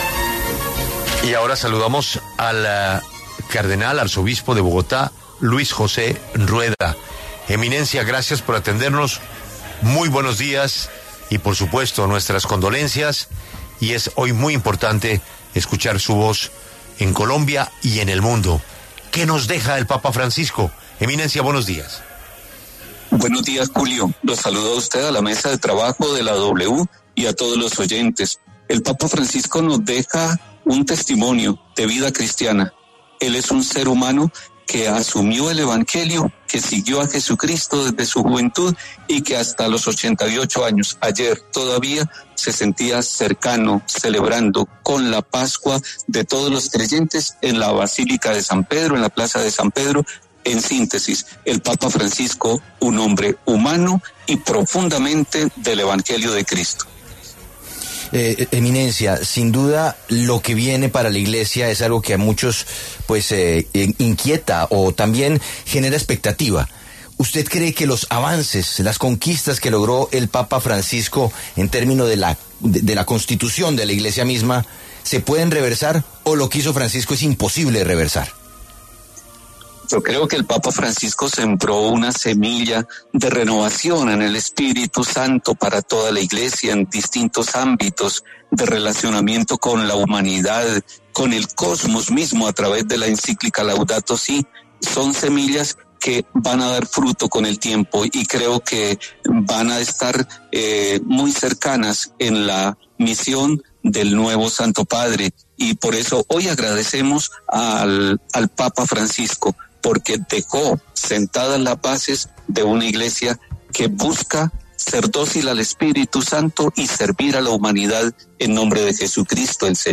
El cardenal Luis José Rueda, arzobispo de Bogotá, habló en La W a propósito de la muerte del papa Francisco.